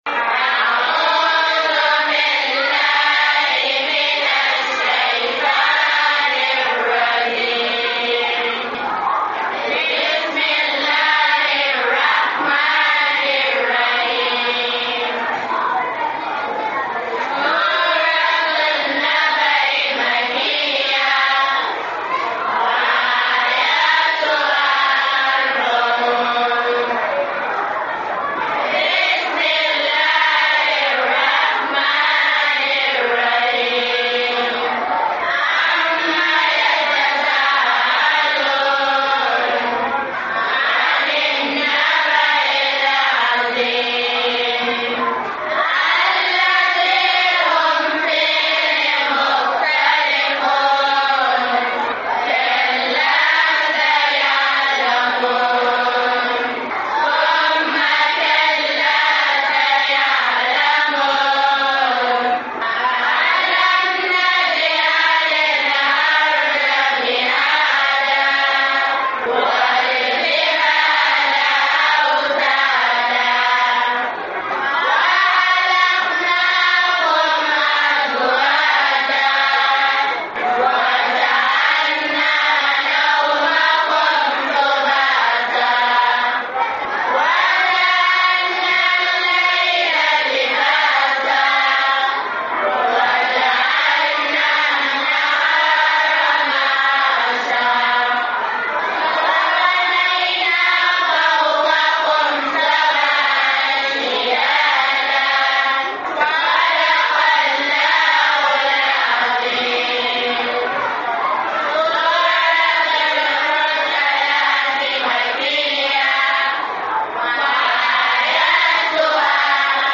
Karatun Alkur’ani daga, Ghana